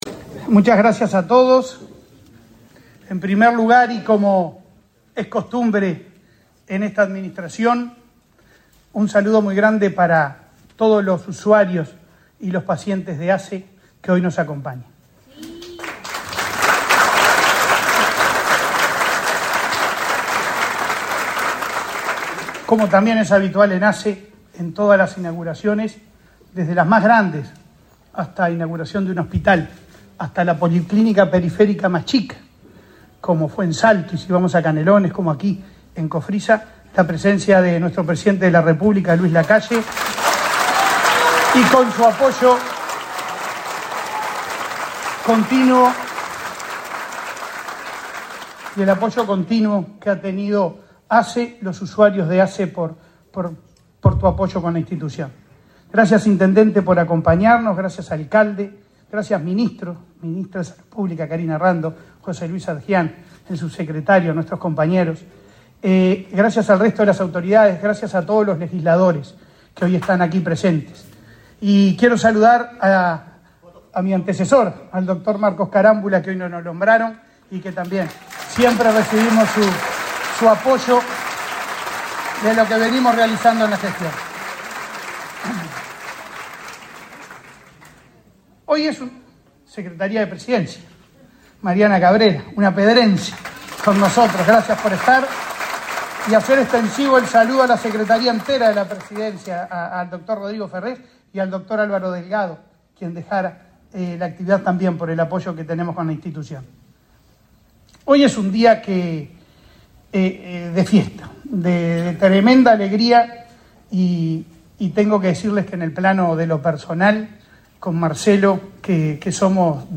Palabras del presidente de ASSE, Leonardo Cipriani
Palabras del presidente de ASSE, Leonardo Cipriani 08/05/2024 Compartir Facebook X Copiar enlace WhatsApp LinkedIn La Administración de los Servicios de Salud del Estado (ASSE) inauguró, este 8 de mayo, obras de remodelación del Centro Auxiliar Dr. Mario Pareja, en Las Piedras. En la oportunidad, disertó el presidente de ASSE, Leonardo Cipriani.